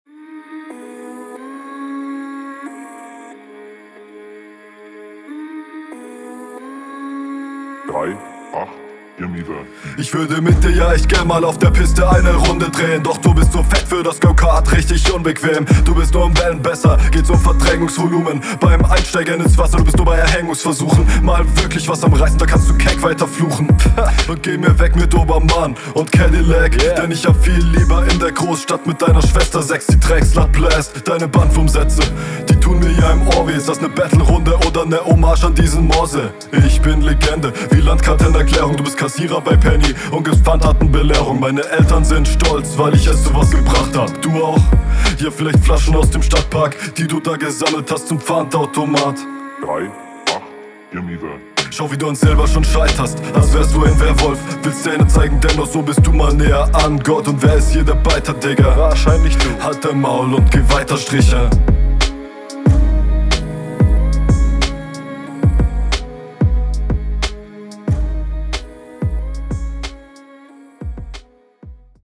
Flow: Außer die Shuffels und n paar leicht offe einsätze am anfang ganz stabil. Gleicher …
Flow: druckvoller und alles in allem mehr in die fresse gefällt mir besser Text: sehe …
Sound ist schon etwas besser als bei deinem Gegner, die Stimme kommt auch viel mächtiger …